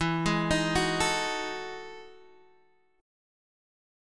E Major 11th